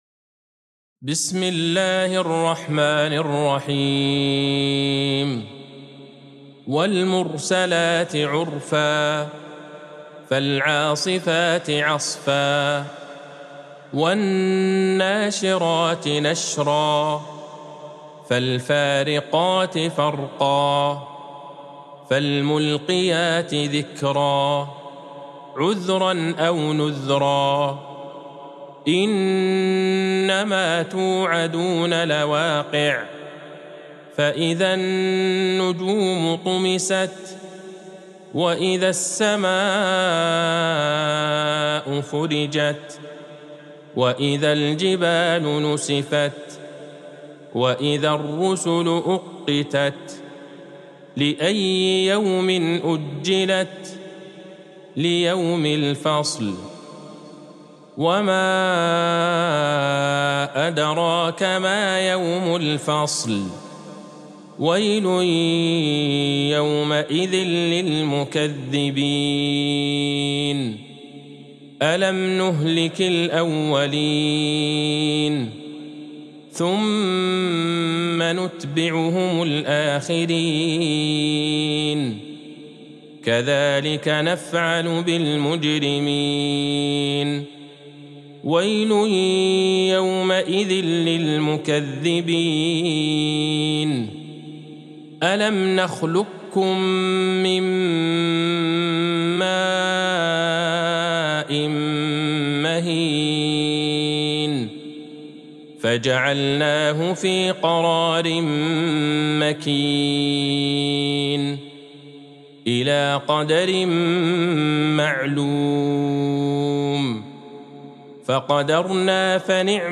سورة المرسلات Surat Al-Mursalat | مصحف المقارئ القرآنية > الختمة المرتلة